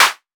Modular Clap 01.wav